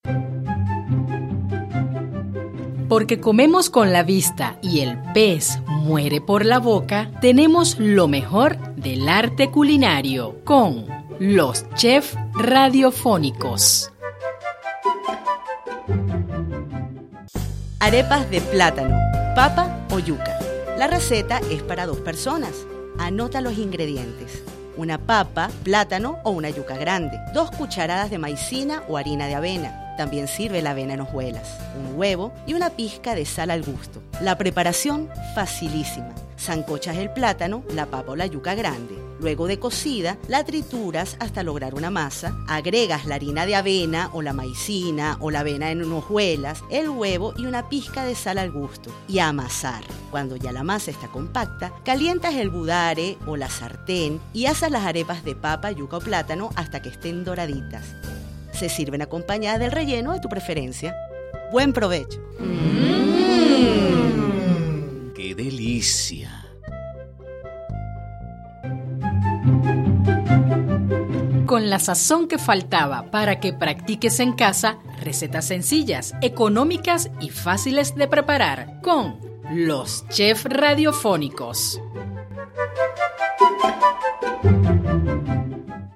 collageEn relación a la guerra económica que está atravesando nuestro país, se han estado implementando alternativas para sustituir aquellos alimentos que se han vuelto difíciles de adquirir, para ello, Alba Ciudad 96.3 FM, de la mano de sus productores, presenta una nueva serie de micros, se trata de “Los chef radiofónicos” donde se le brinda a nuestros radios escuchas, varias recetas económicas y de fácil alcance para hacer en el hogar.